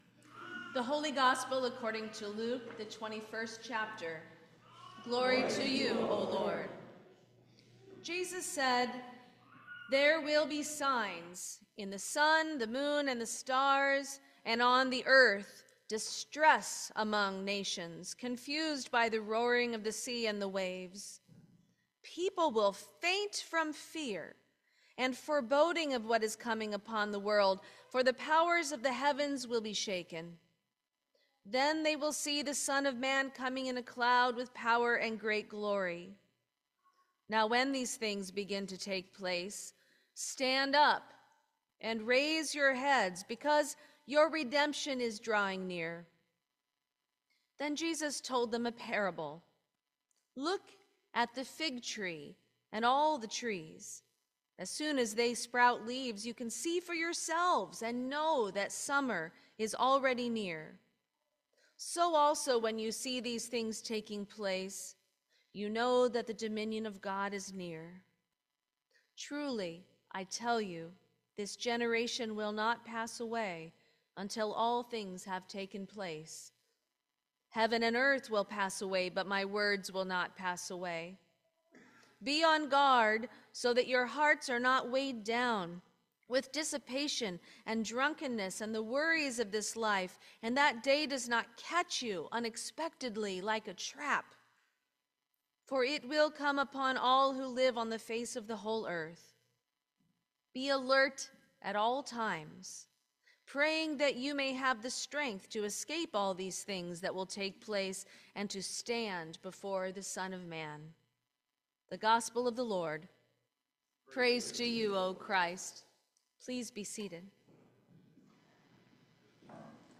Sermon for the First Sunday of Advent 2024